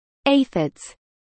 Aphids-Pronunciation.mp3